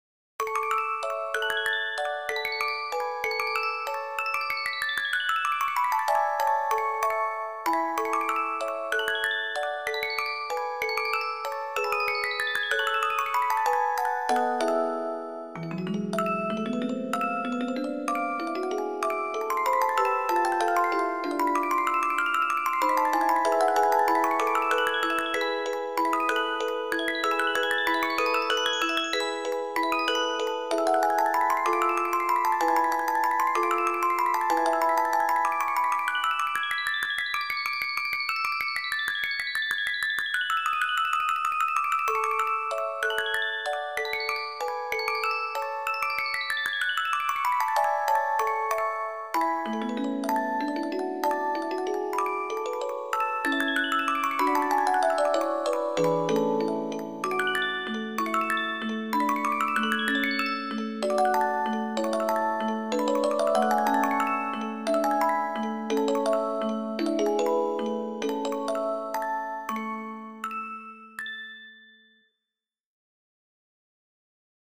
クラシック曲（作曲家別）－MP3オルゴール音楽素材